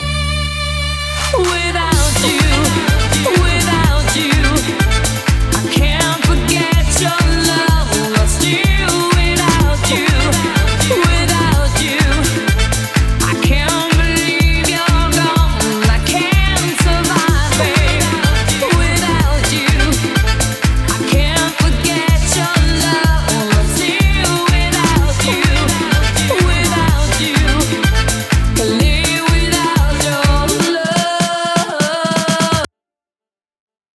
Disco Remix